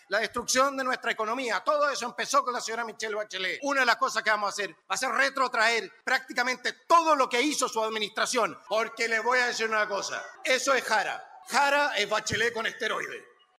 Cerca de tres mil personas, en su mayoría militantes del Partido Nacional Libertario, participaron en la proclamación oficial del diputado Johannes Kaiser como candidato presidencial de la colectividad.
En un tono exaltado, lanzó críticas directas hacia la abanderada del oficialismo, Jeannette Jara, y a la expresidenta Michelle Bachelet, a quien responsabilizó de muchos de los problemas actuales del país, acusándola de haber provocado retrocesos económicos y de facilitar la inmigración ilegal.